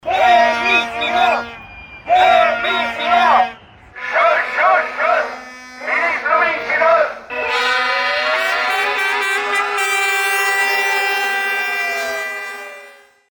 După trei săptămâni de proteste la Ministerul Educației, sindicatele din învățământ s-au mutat în Piața Victoriei, unde, de la ora 12:00, pichetează sediul Guvernului.
02sept-13-scandari-protest-profesori.mp3